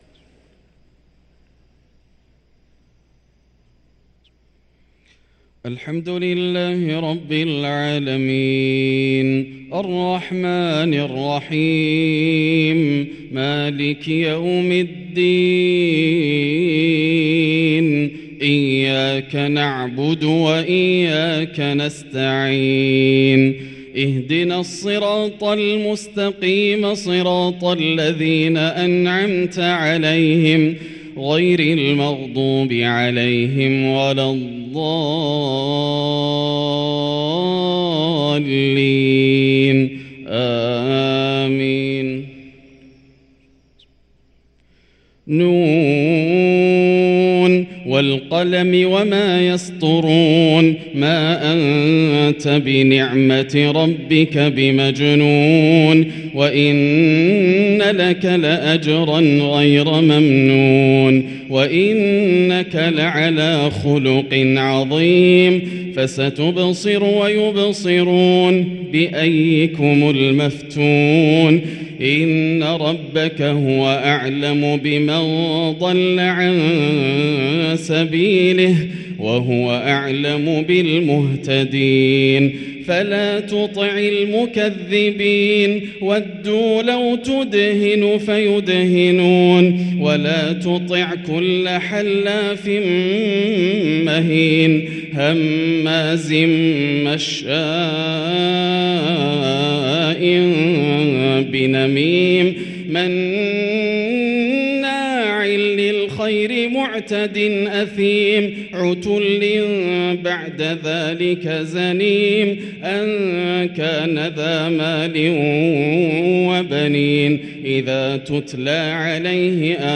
صلاة الفجر للقارئ ياسر الدوسري 24 شعبان 1444 هـ
تِلَاوَات الْحَرَمَيْن .